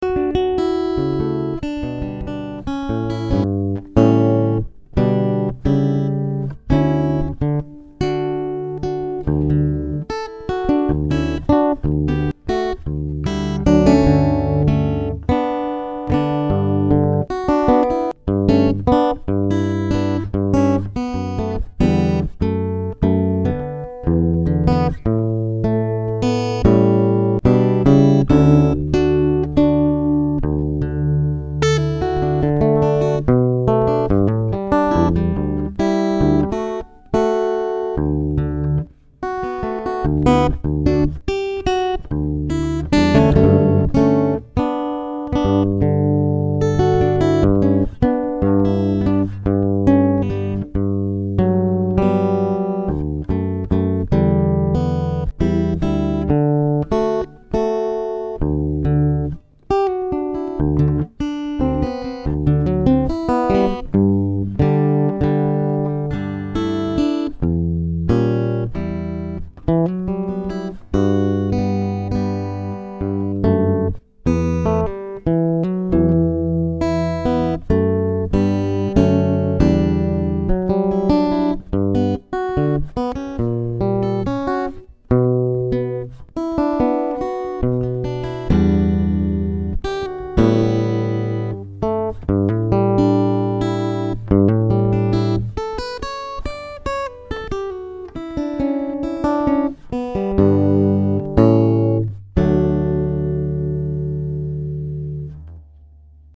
D, G, D, G, B, E
It's a VERY difficult fingerpicking song.